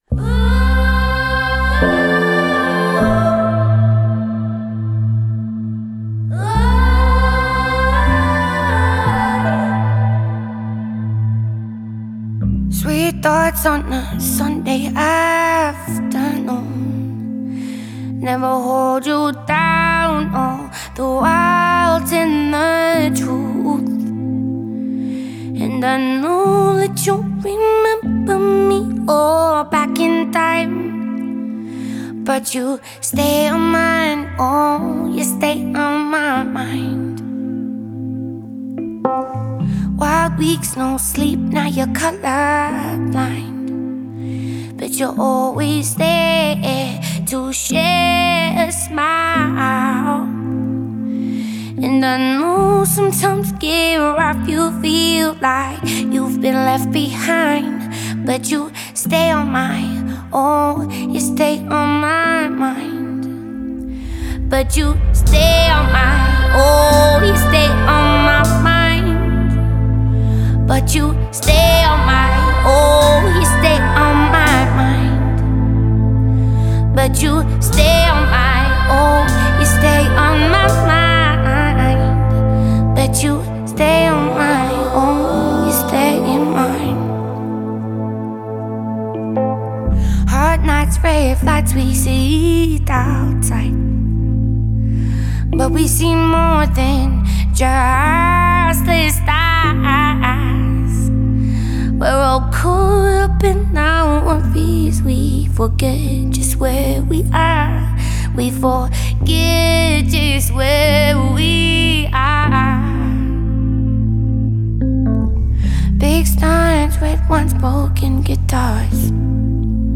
Альтернатива